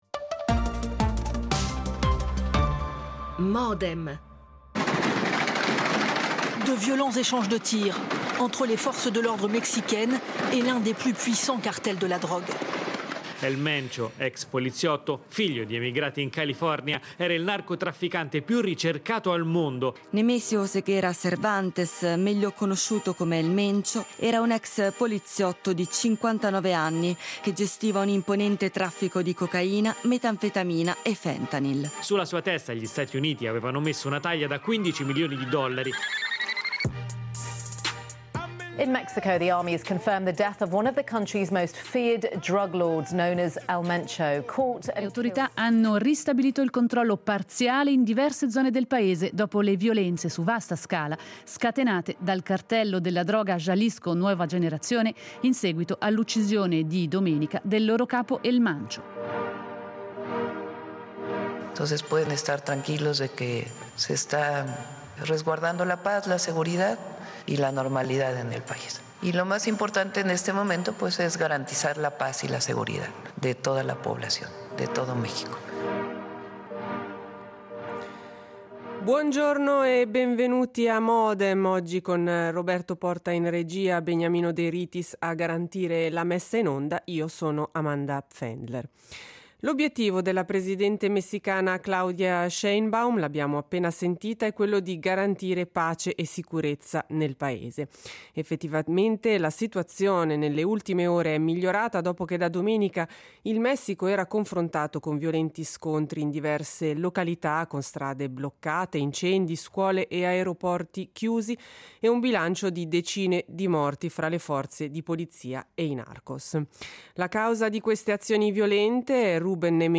Ne parliamo con tre ospiti:
L'attualità approfondita, in diretta, tutte le mattine, da lunedì a venerdì